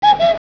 cuco1.wav